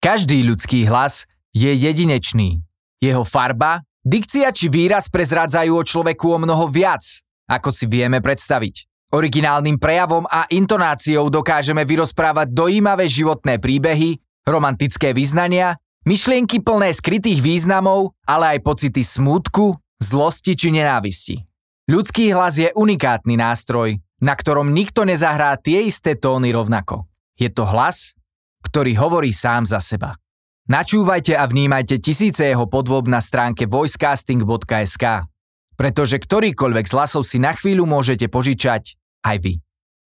Umím: Voiceover